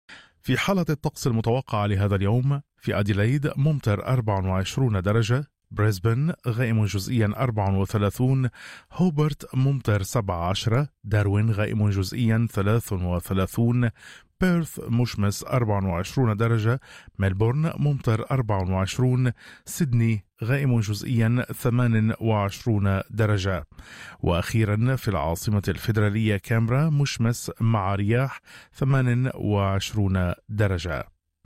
حالة الطقس في أستراليا: الجمعة 28/11/2025